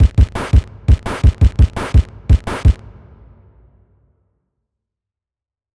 drums1.wav